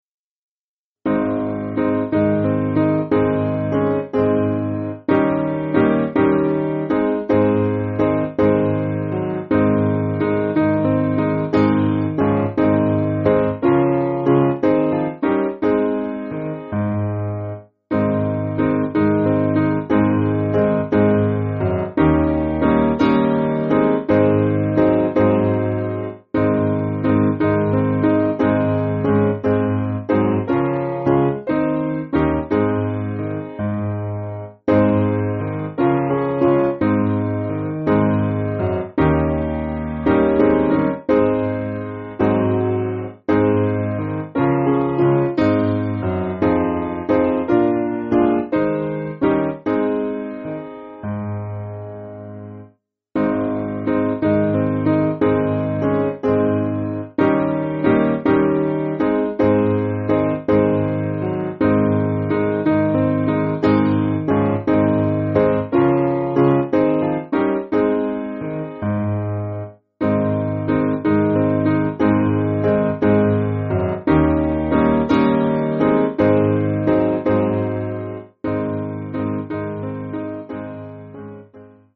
Piano and Flute